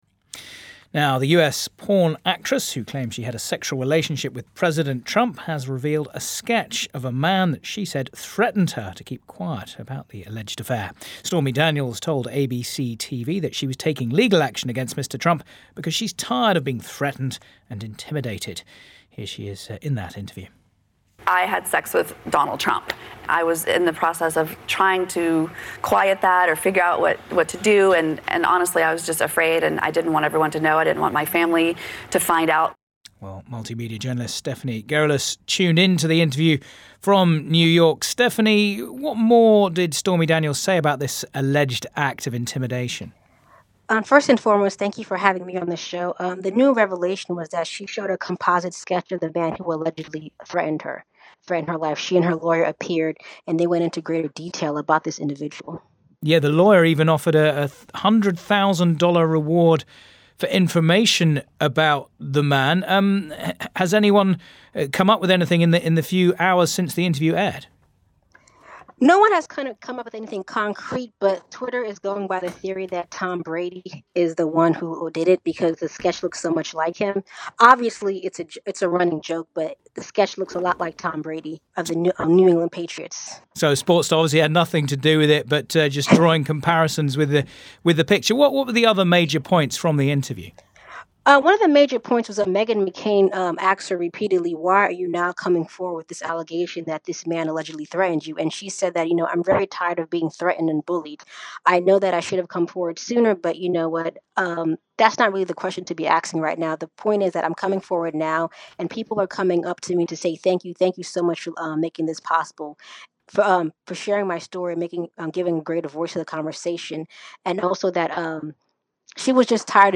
BBC Interview
stormy-daniels-two-way.mp3